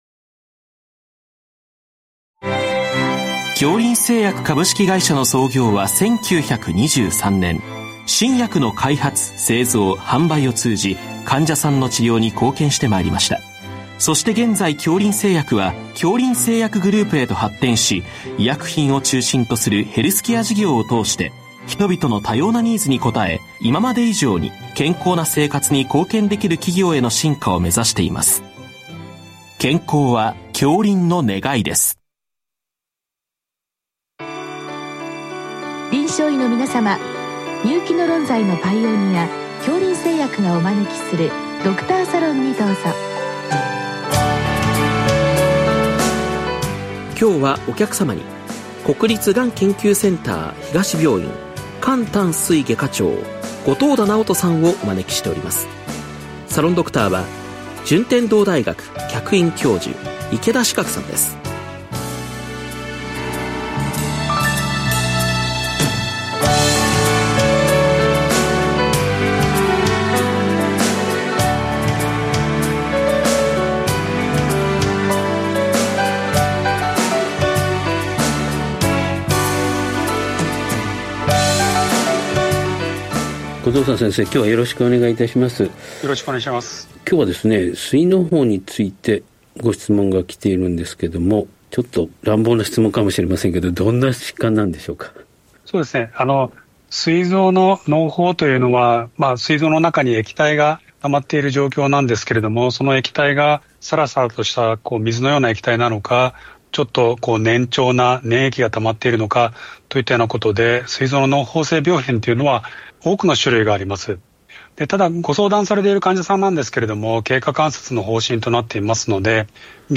全国の臨床医の方々にむけた医学情報番組。臨床医の方々からよせられたご質問に、各分野の専門医の方々にご出演いただき、解説いただく番組です。